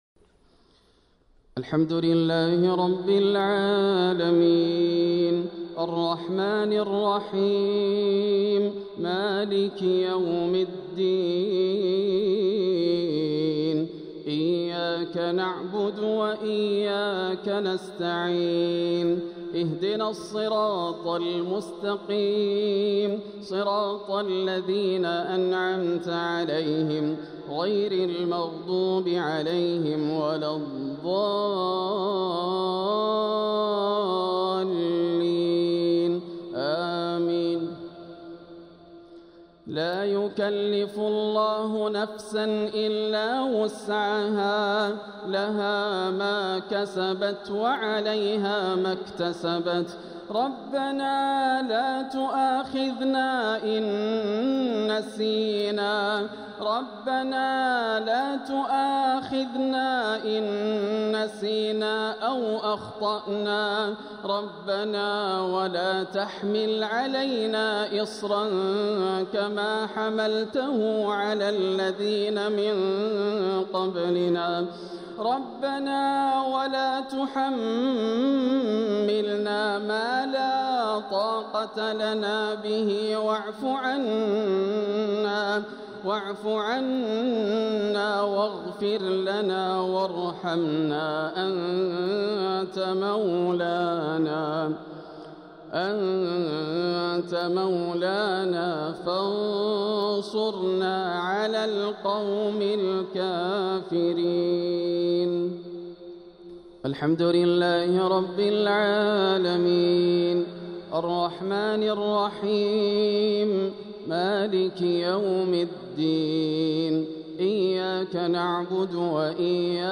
تلاوة من سورتي البقرة 286 و إبراهيم 35 | صلاة الجمعة 28 رمضان 1446هـ > عام 1446 > الفروض - تلاوات ياسر الدوسري